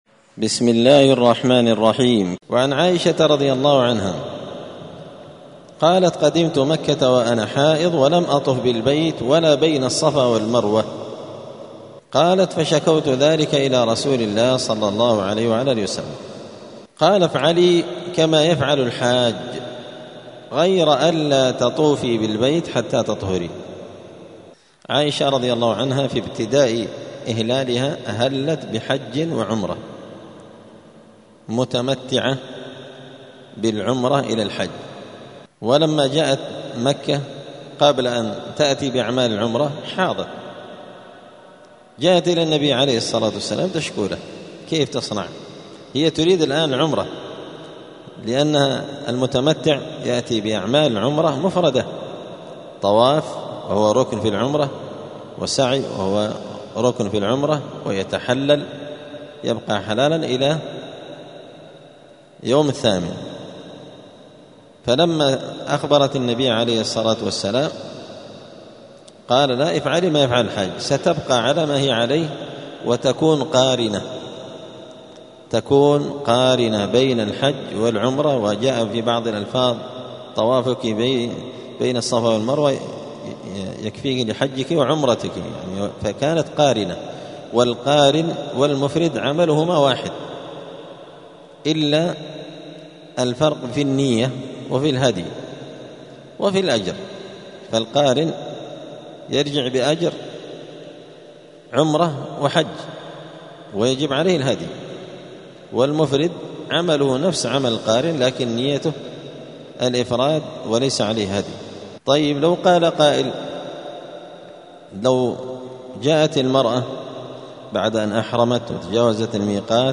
دار الحديث السلفية بمسجد الفرقان قشن المهرة اليمن
*الدرس الثاني المائة [102] {باب الحيض حكم الطواف بالبيت للحائض}*